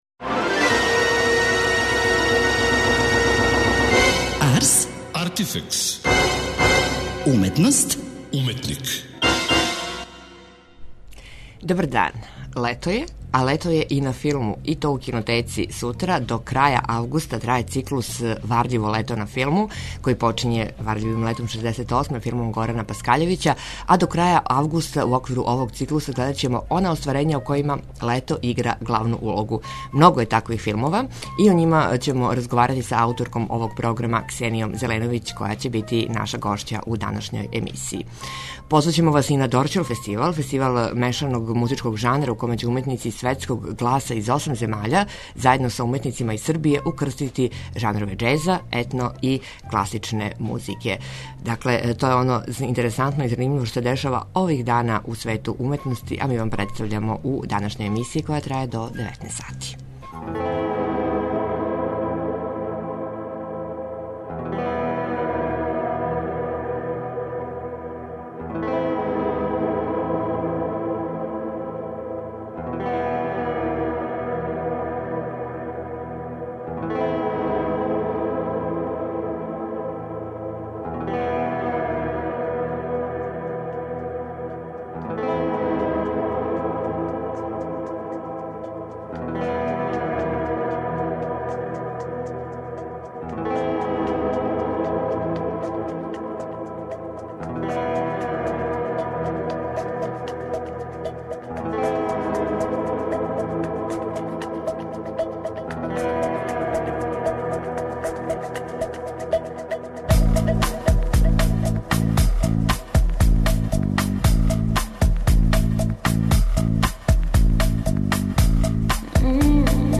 преузми : 26.75 MB Ars, Artifex Autor: Београд 202 Ars, artifex најављује, прати, коментарише ars/уметност и artifex/уметника.